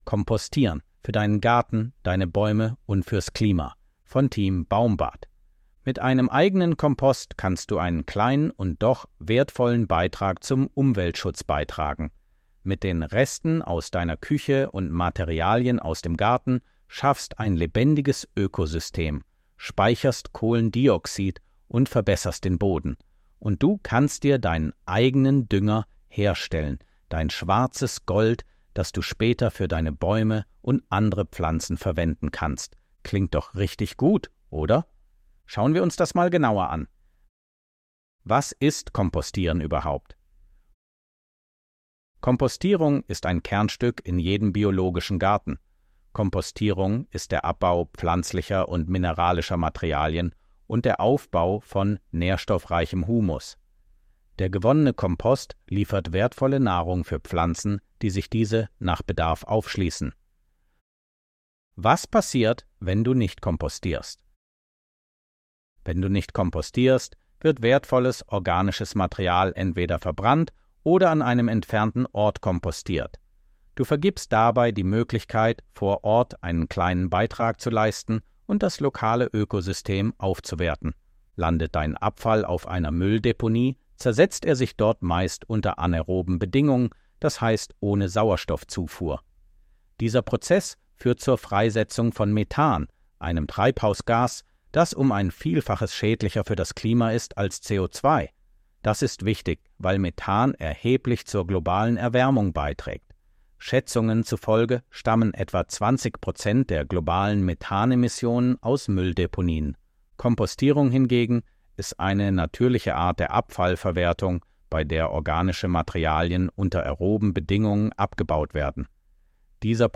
von Team baumbad 30.09.2024 Artikel vorlesen Artikel vorlesen Mit einem eigenen Kompost kannst du einen kleinen und doch wertvollen Beitrag zum Umweltschutz beitragen.